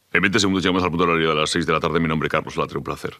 FM
Recreació feta per Carlos Latre al programa "Col·lapse" de 3Cat (TV 3) emès el 31 de maig de l'any 2025